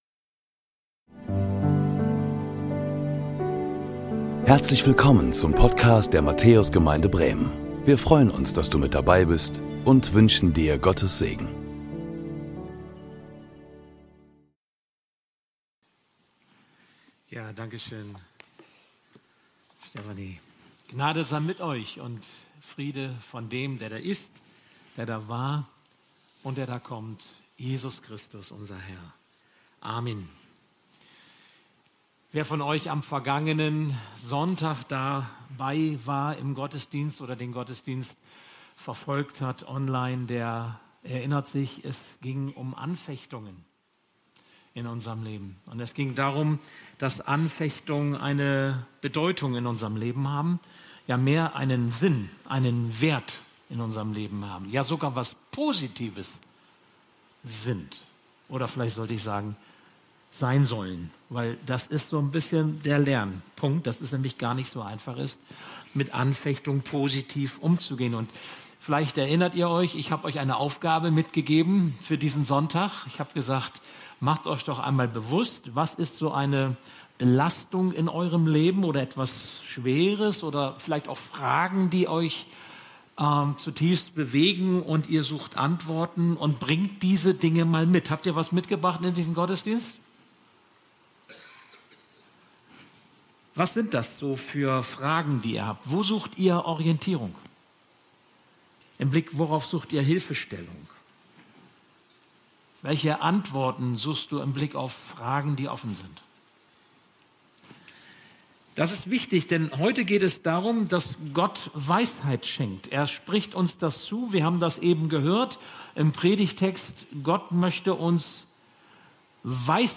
Willkommen zu unserem 10 Uhr Gottesdienst live aus der Matthäus Gemeinde Bremen!
Predigttext: Jakobus 1, 5–8; Mehr